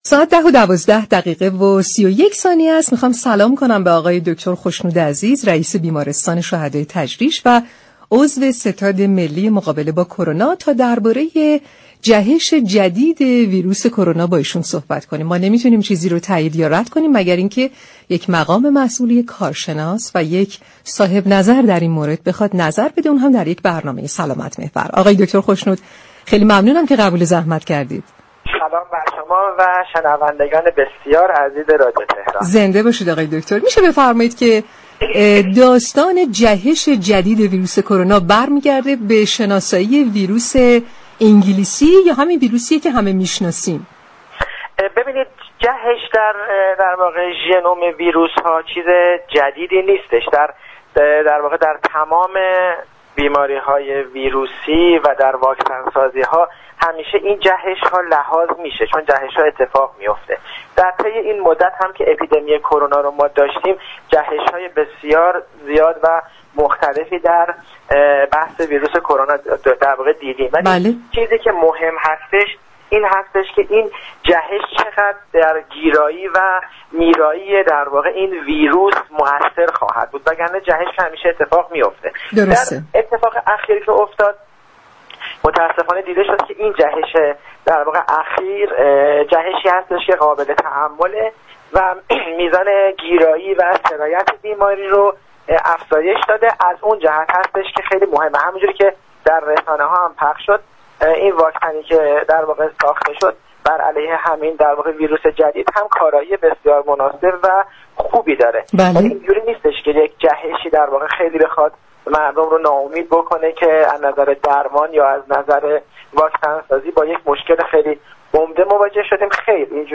در گفتگو با برنامه تهران ما سلامت